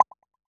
Button Navigation Select Team 18.wav